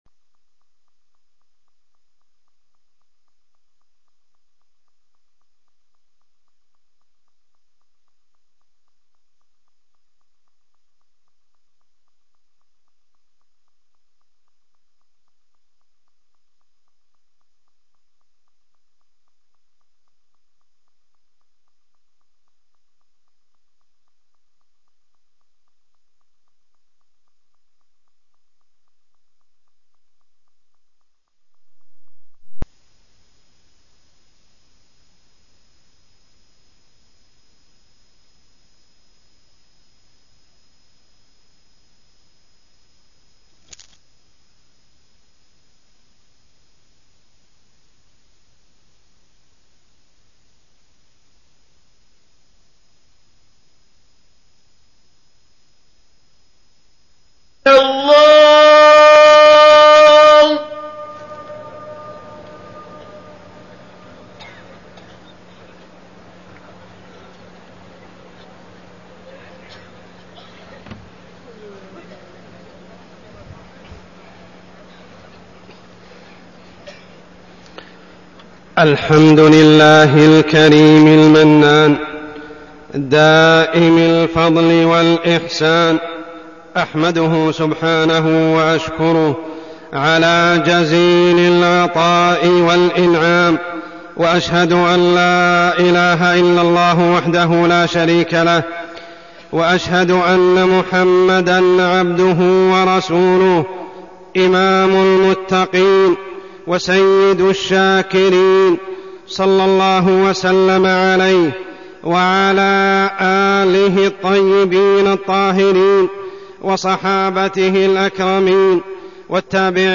تاريخ النشر ٢٠ ذو الحجة ١٤١٨ هـ المكان: المسجد الحرام الشيخ: عمر السبيل عمر السبيل الحمد والشكر